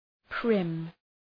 {prım}